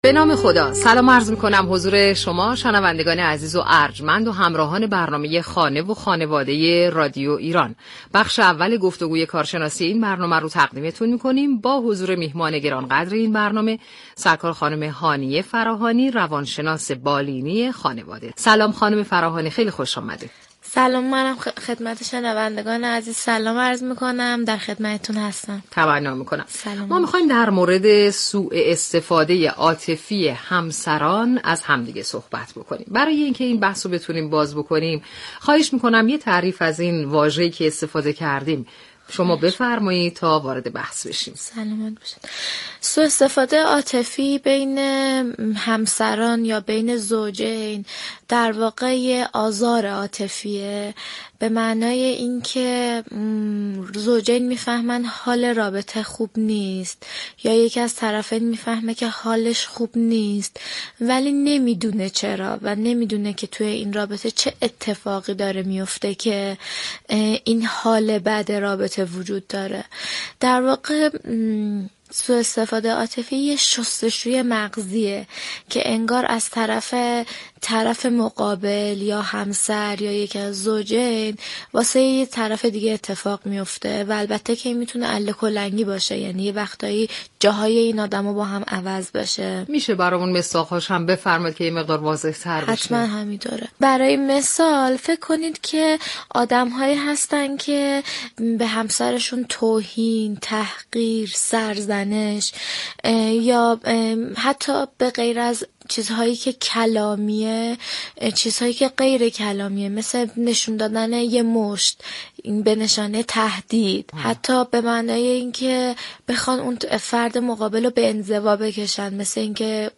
روانشناس بالینی